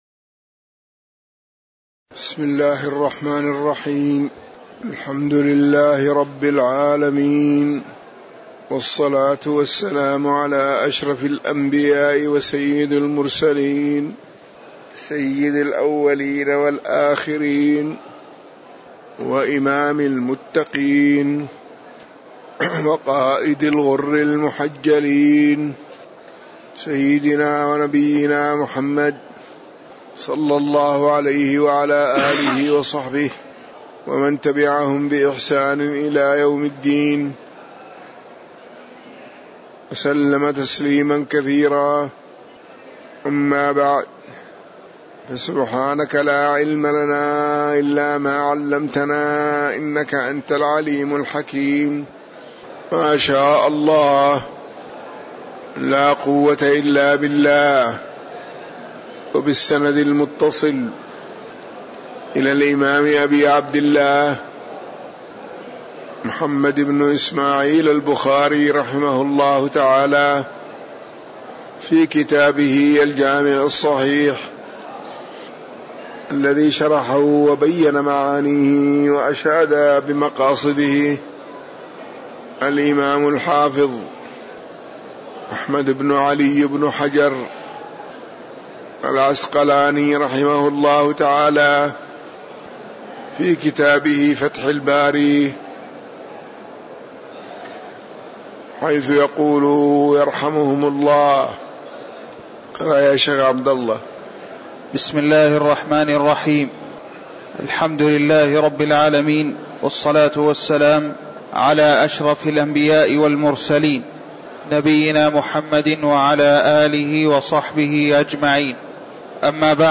تاريخ النشر ٢٩ ربيع الأول ١٤٤١ هـ المكان: المسجد النبوي الشيخ